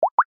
Короткие рингтоны
Рингтоны на смс и уведомления